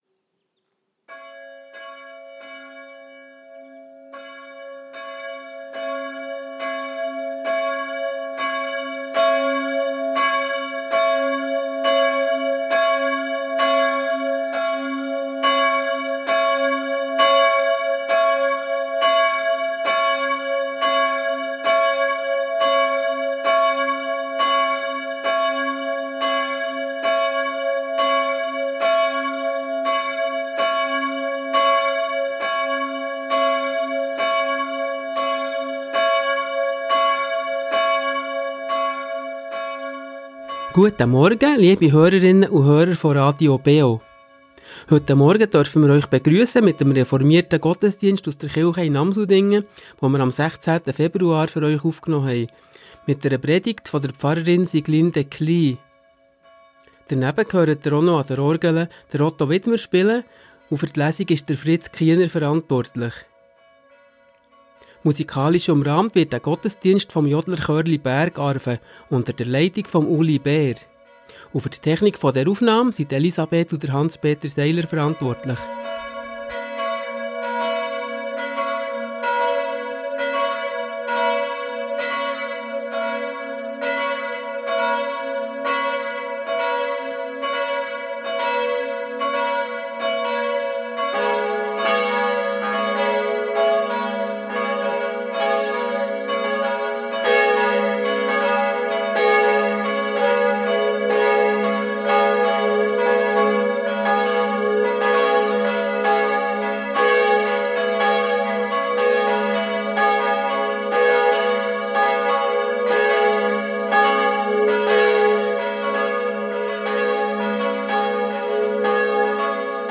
Reformierte Kirche Amsoldingen ~ Gottesdienst auf Radio BeO Podcast